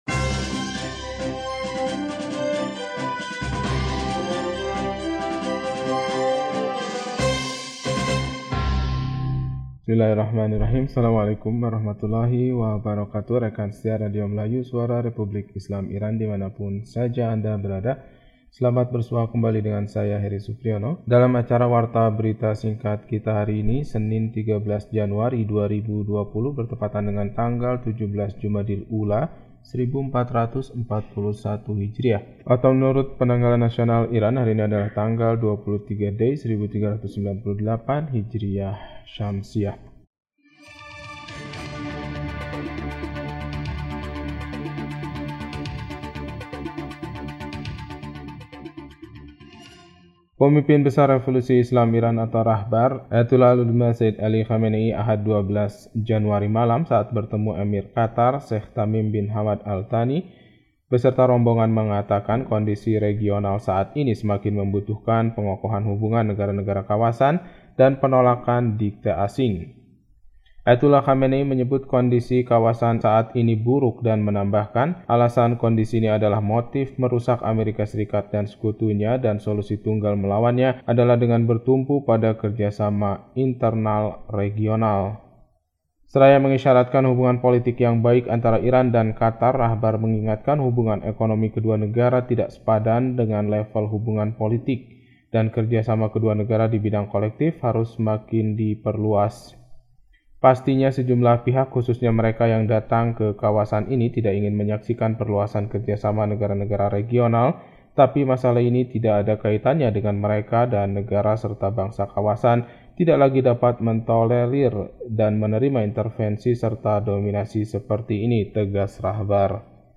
Berita 13 Januari 2020